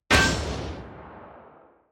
hitmarker1.wav